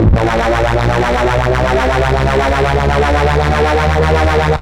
bass m 2.110.wav